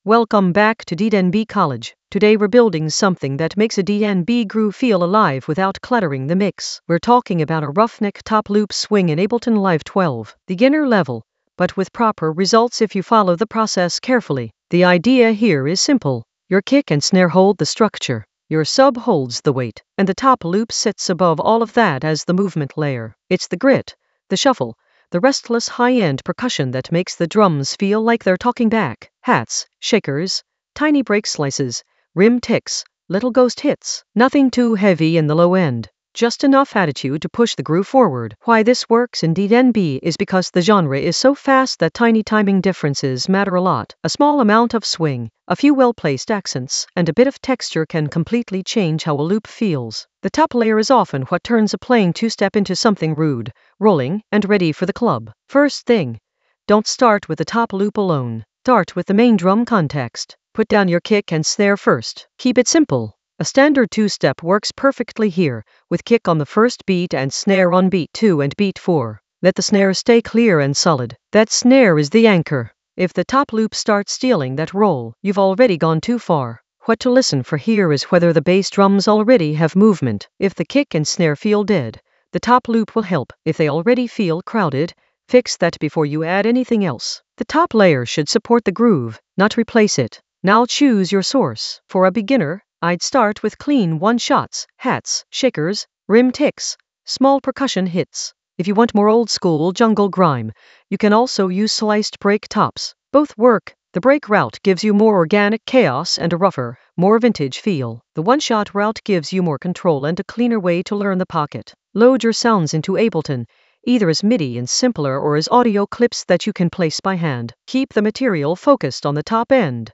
An AI-generated beginner Ableton lesson focused on Ruffneck approach: a top loop swing in Ableton Live 12 in the Mastering area of drum and bass production.
Narrated lesson audio
The voice track includes the tutorial plus extra teacher commentary.